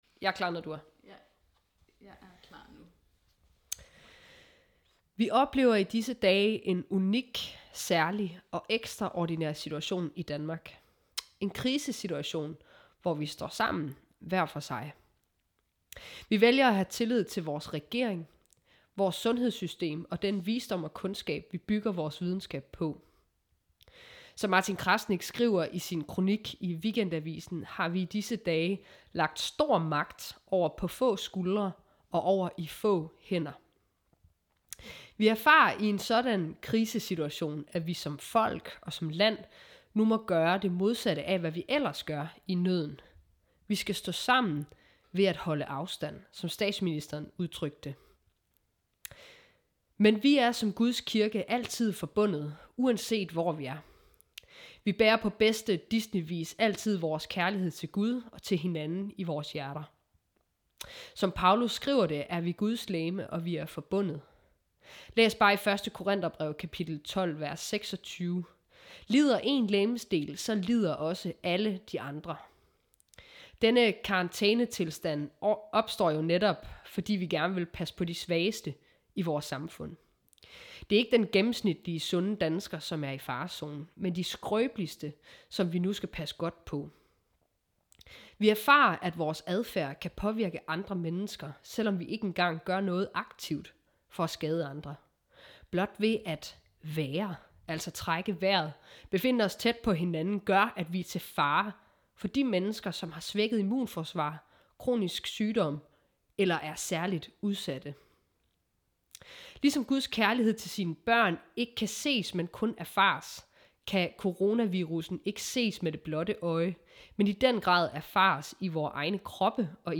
Prædiken